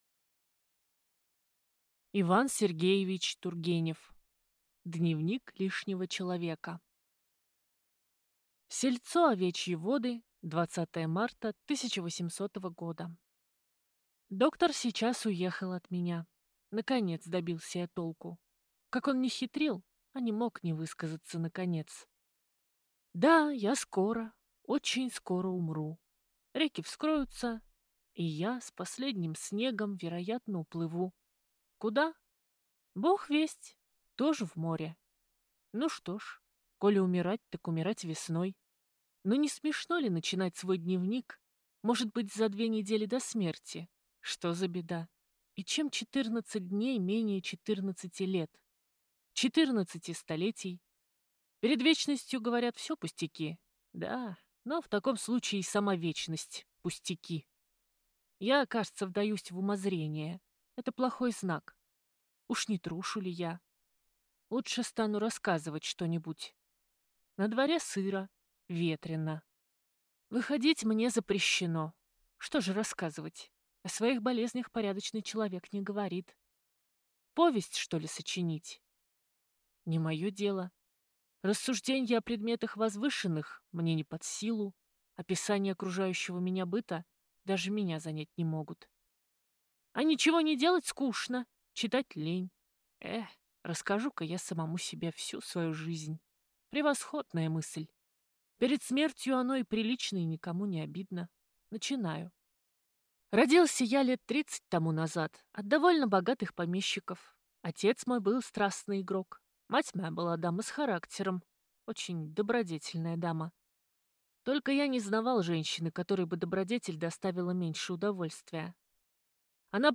Аудиокнига Дневник лишнего человека | Библиотека аудиокниг